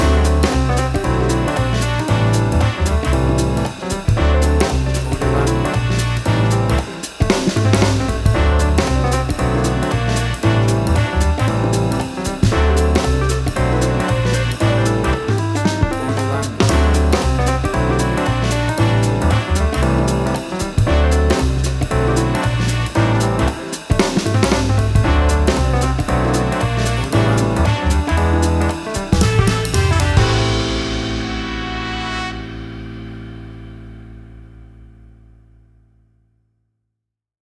A swinging blend of the Jazz music genres and styles
Tempo (BPM): 115